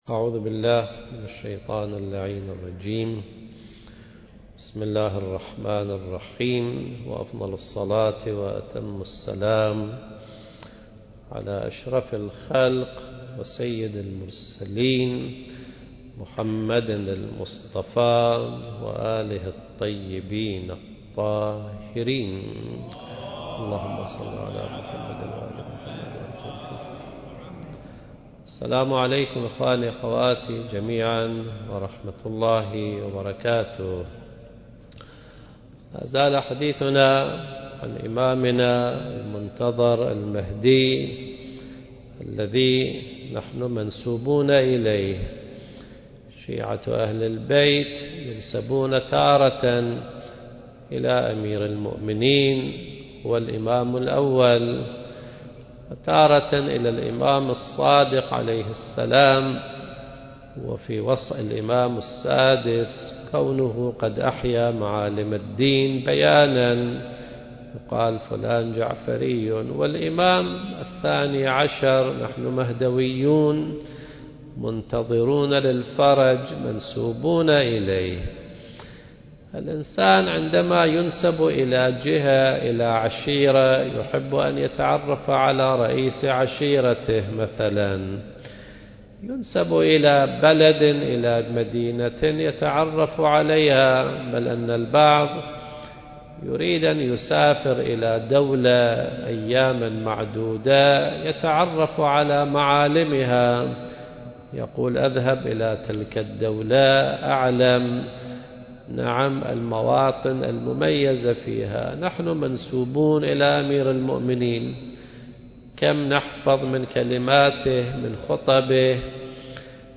المكان: مسجد الصديقة فاطمة الزهراء (عليها السلام)